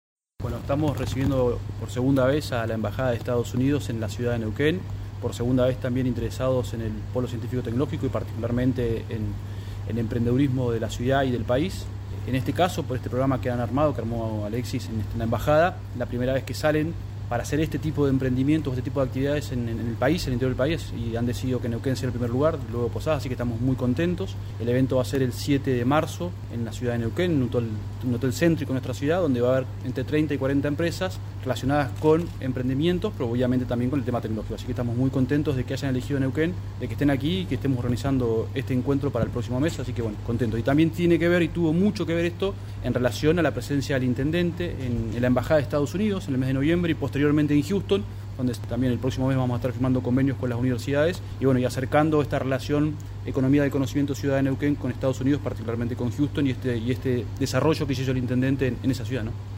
Gastón Contardi, secretario de Innovación y Proyectos Estratégicos.
Gaston-Contardi-EDITADO-Visita.mp3